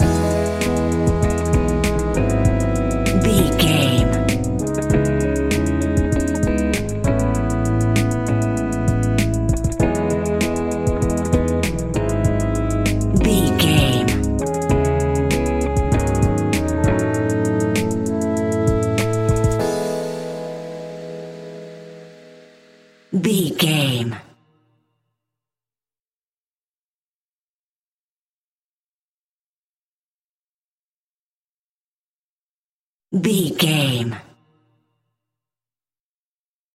Ionian/Major
instrumentals
chilled
laid back
groove
hip hop drums
hip hop synths
piano
hip hop pads